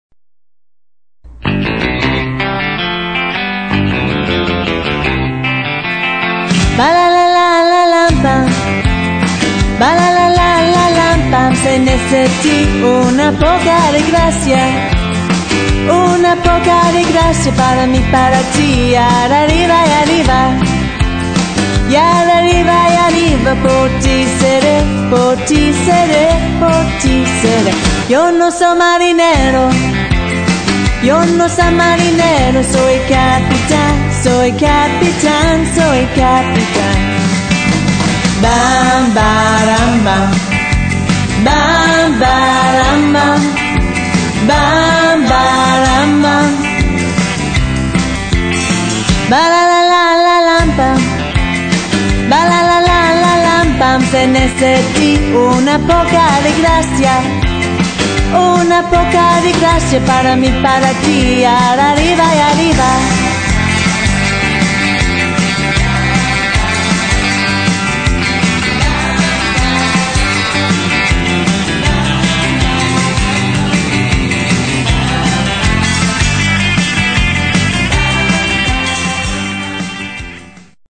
• Six-piece band
• Two female lead vocalists